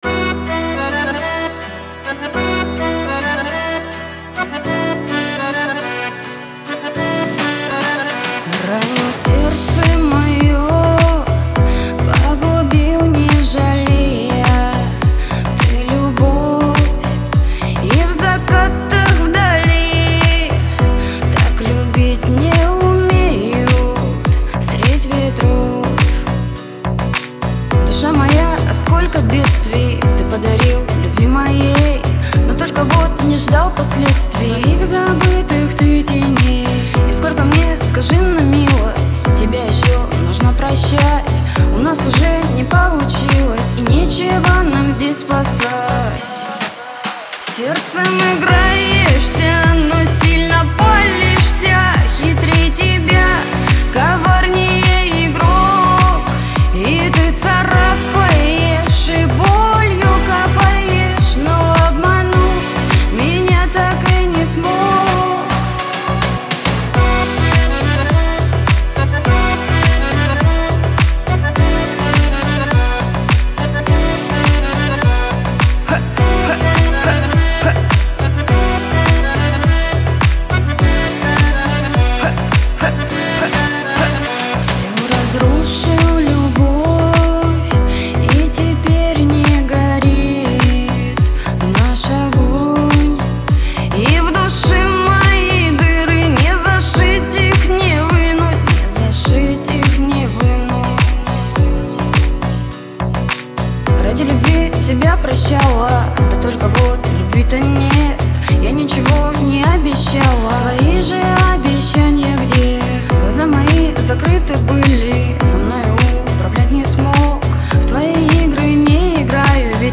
ХАУС-РЭП